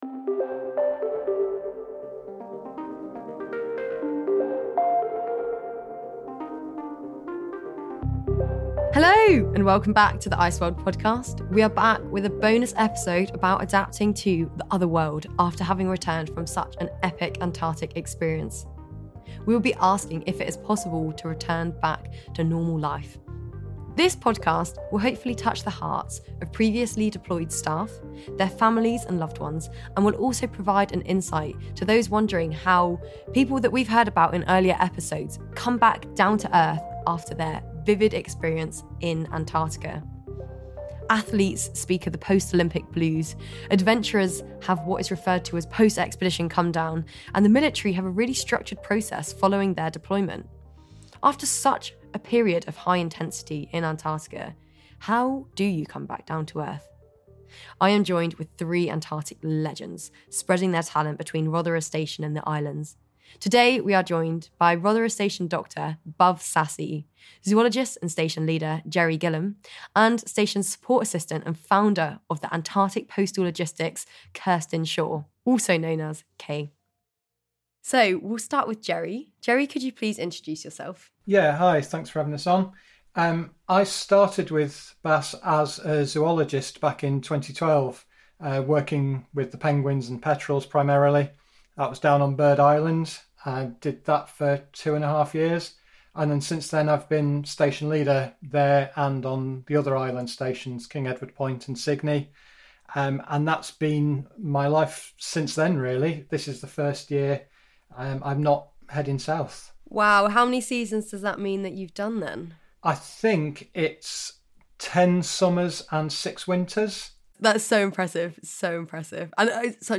From polar scientists to plumbers, ICEWORLD is a series of interviews with ordinary people who are doing extraordinary jobs in Antarctica. The team talk climate science, extreme living, expeditions and becoming a community.